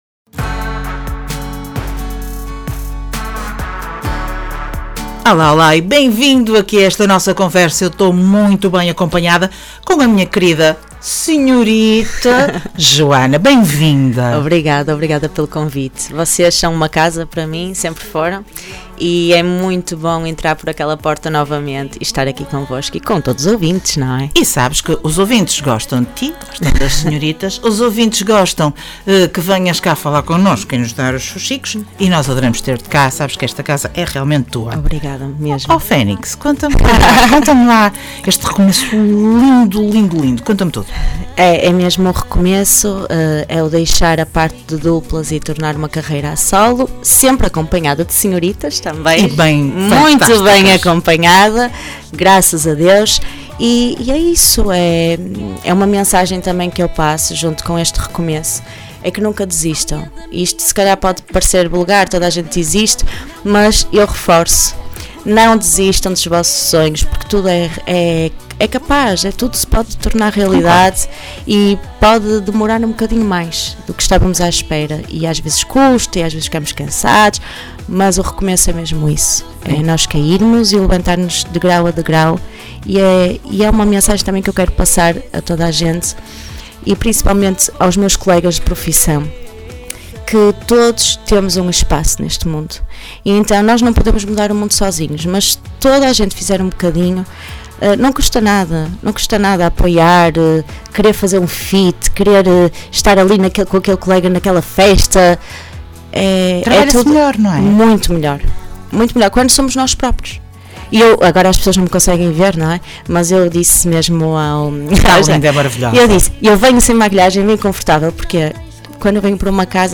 Entrevista Senhoritas